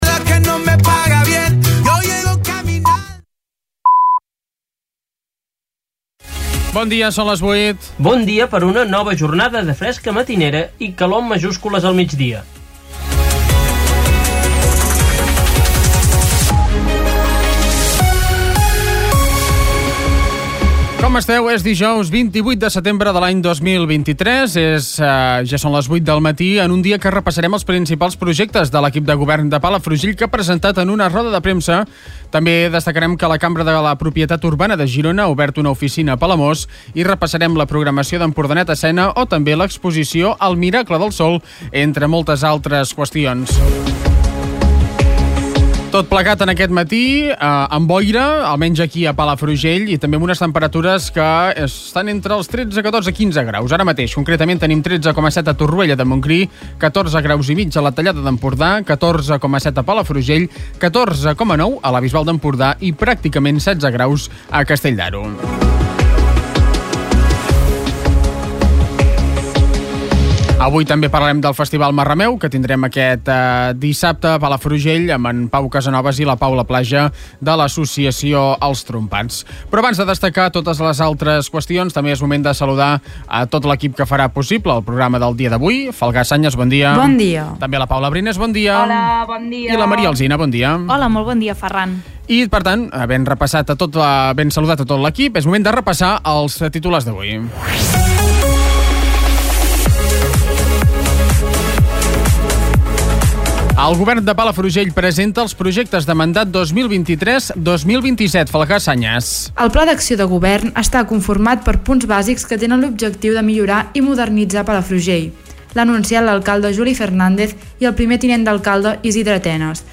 Escolta l'informatiu d'aquest dijous